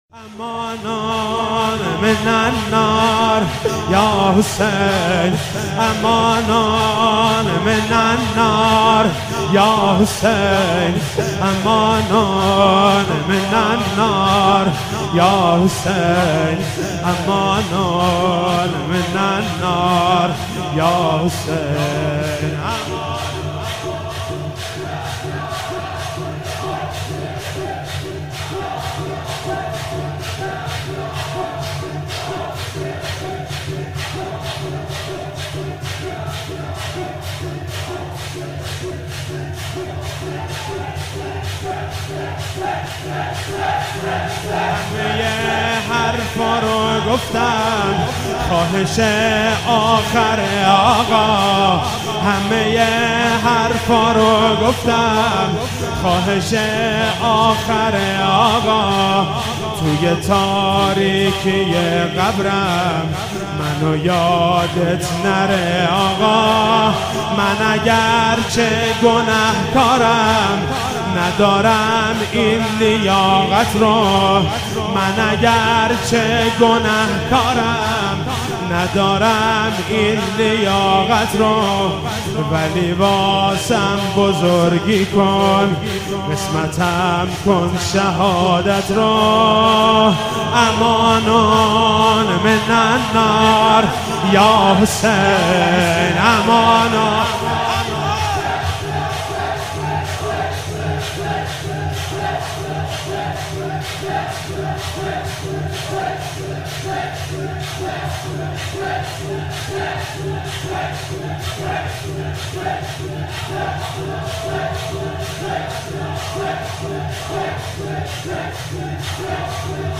شب 22 ماه مبارک رمضان 96(قدر) -شور - همه حرف رو گفتم خواهش آخر آقا
شور مداحی